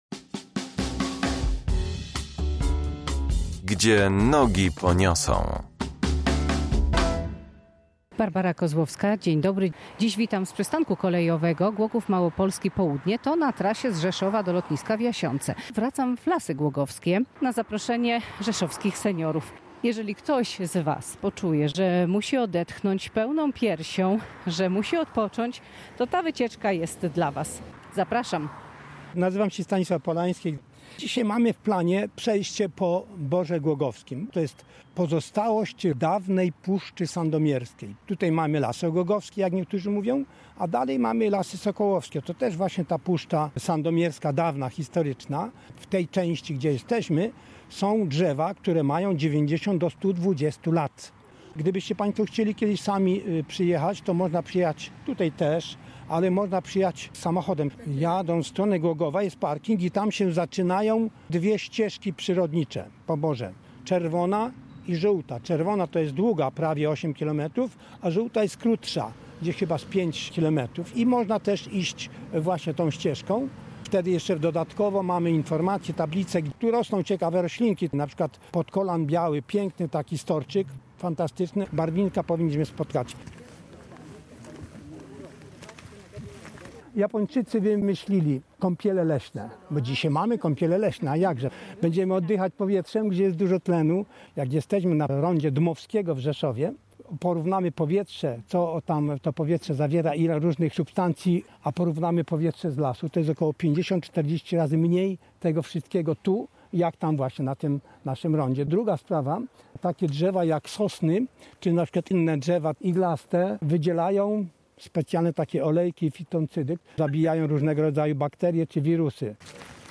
W ramach tegorocznych rzeszowskich Senioraliów tę właśnie trasę wybrali uczestnicy spaceru z przewodnikiem